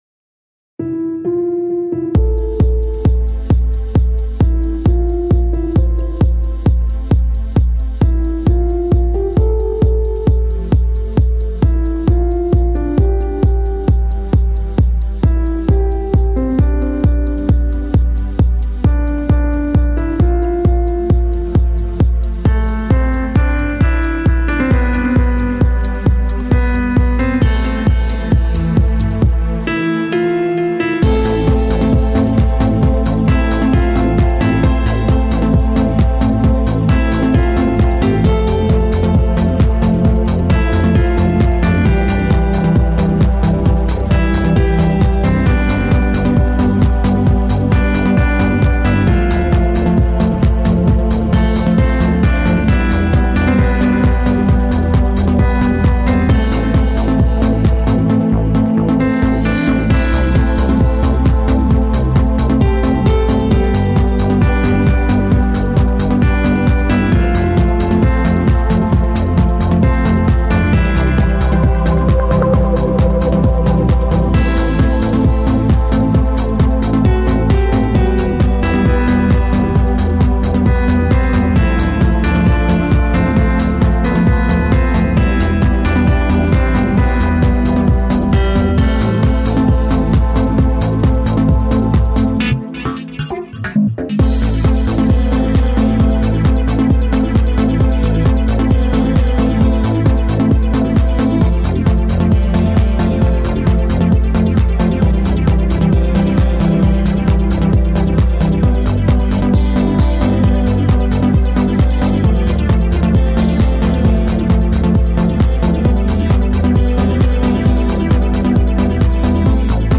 Žánr: Electro/Dance
místy zase spíš zklidňující.